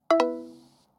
hang_up_sound.mp3